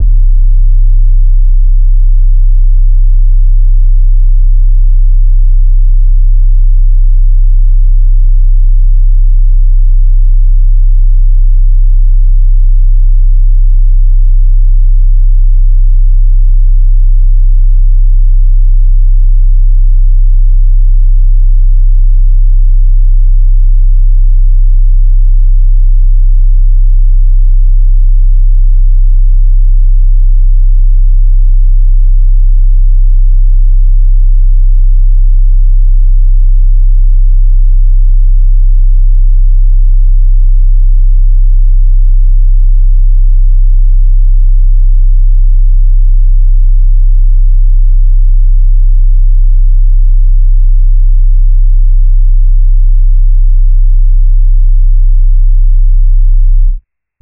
[ACD] - Lex 808 (2).wav